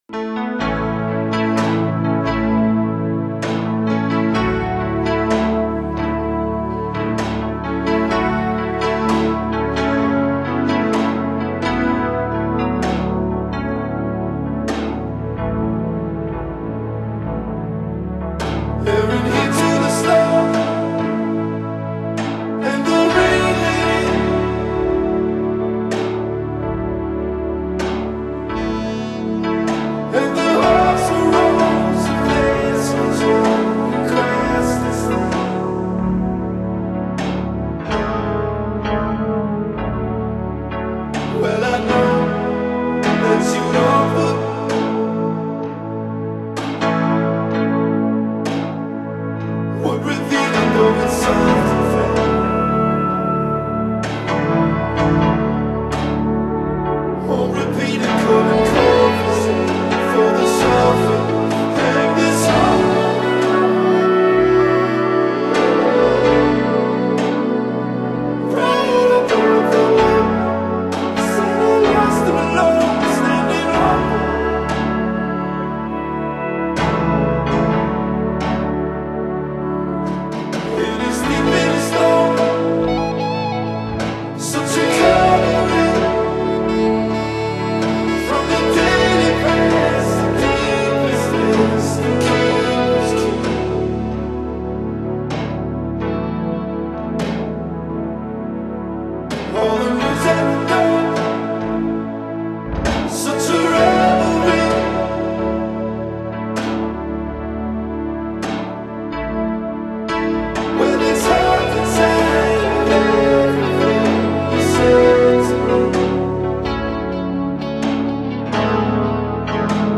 凜冽氣息瀰漫在音符之中，淡淡哀愁感藉由弦樂流出。